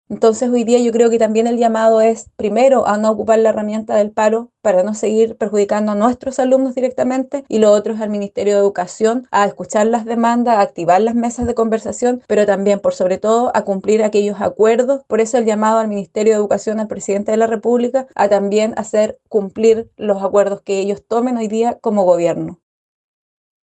La diputada Karen Medina argumentó que está en contra de la paralización porque perjudica a los alumnos y la enseñanza.